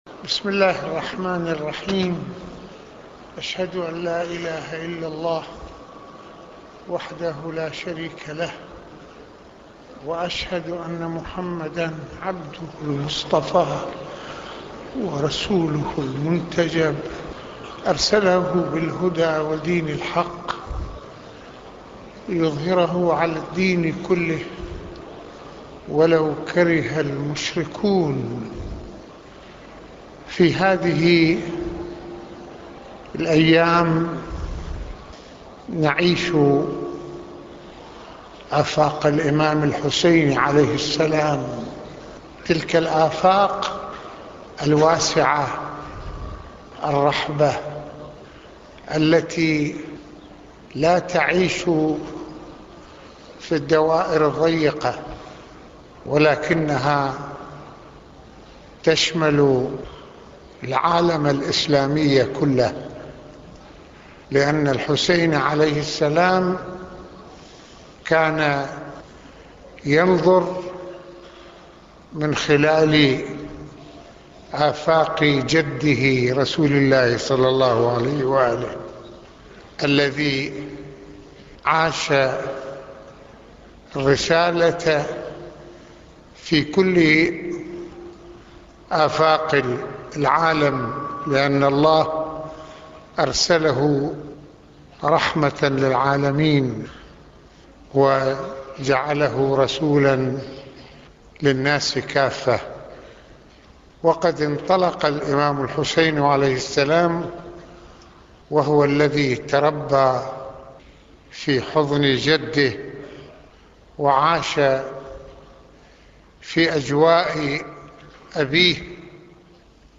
خطبة الجمعة المكان : مسجد الامامين الحسنين